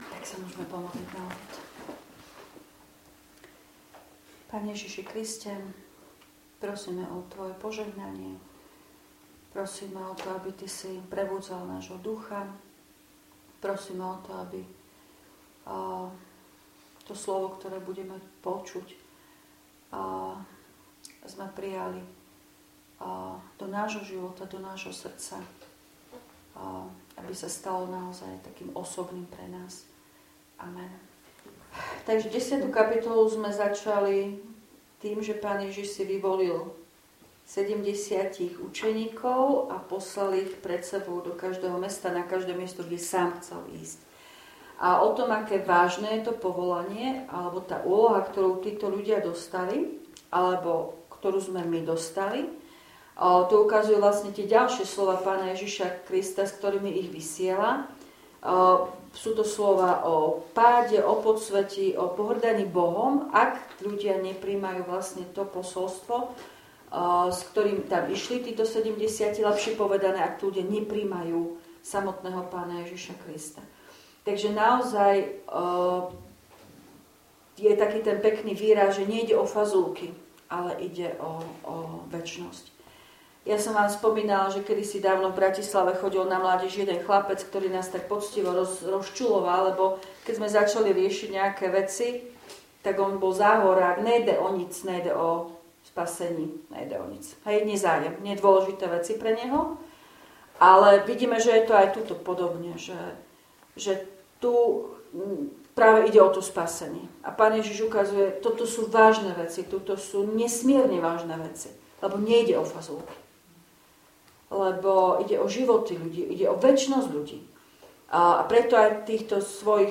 Biblická hodina zo dňa 27.10.2025
V nasledovnom článku si môžete vypočuť zvukový záznam z biblickej hodiny zo dňa 27.10.2025.